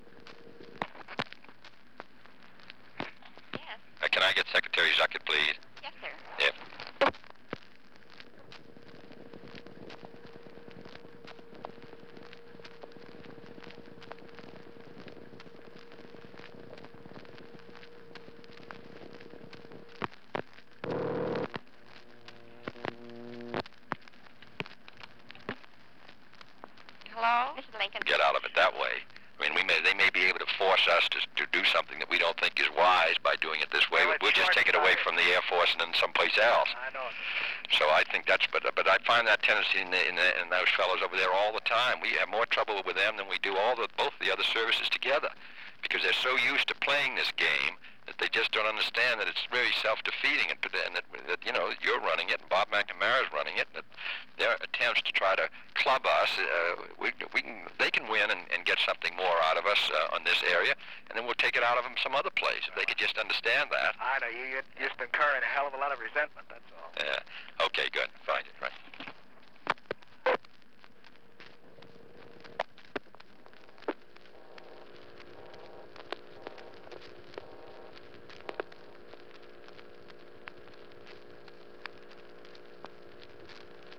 Conversation with Eugene Zuckert
Secret White House Tapes | John F. Kennedy Presidency Conversation with Eugene Zuckert Rewind 10 seconds Play/Pause Fast-forward 10 seconds 0:00 Download audio Previous Meetings: Tape 121/A57.